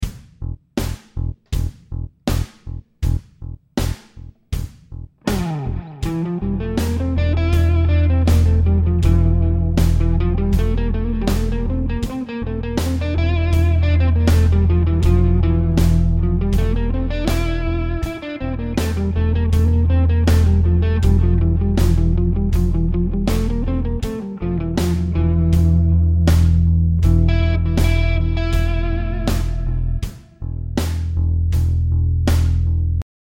The bass is just playing the E note in all examples.
Locrian
I find this one the toughest as it doesn't really sound too great in my opinion.
Even though we are using the same notes that appear in F major, it really doesn't sound major at all with the focus being n the E!